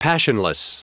Prononciation du mot passionless en anglais (fichier audio)
Prononciation du mot : passionless
passionless.wav